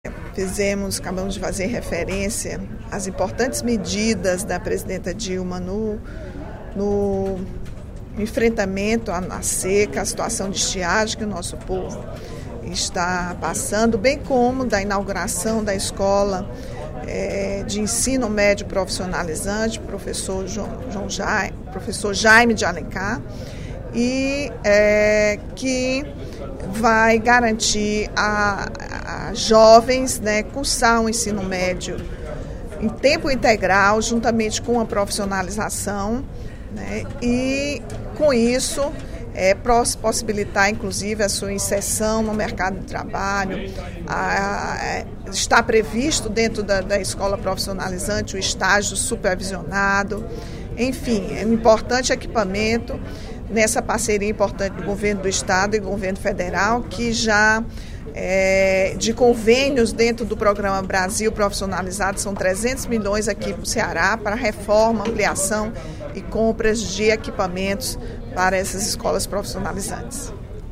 A deputada Rachel Marques, líder do PT, ocupou a tribuna do Plenário 13 de Maio, na sessão plenária desta quarta-feira (03/04), para rebater afirmações do deputado Fernando Hugo (PSDB) a respeito do programa federal Bolsa Família.